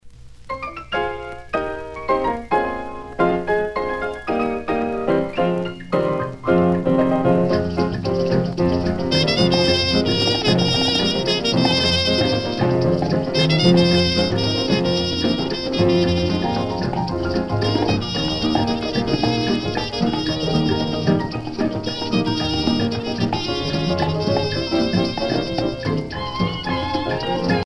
danse : rumba